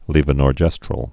(lēvə-nôr-jĕstrəl)